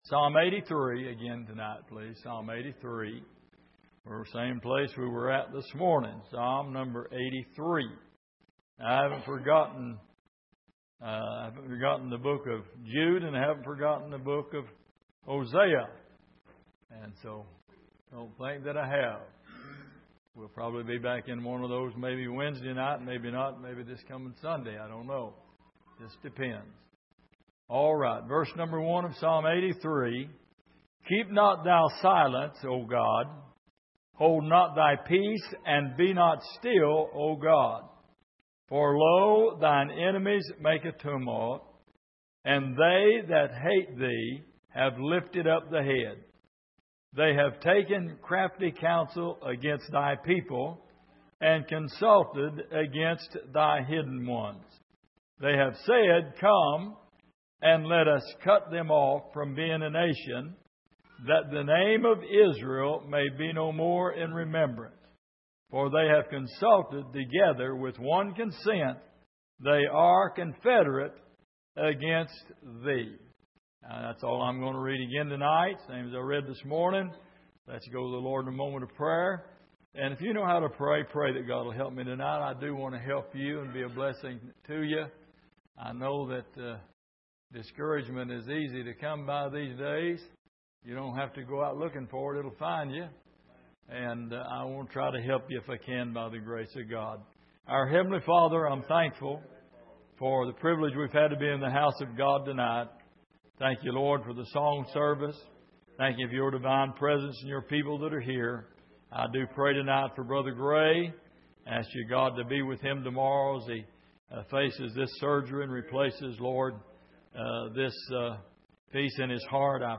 Exposition of the Psalms Passage: Psalm 83:1-5 Service: Sunday Evening He Hideth My Soul « When God Is Silent When God Is Silent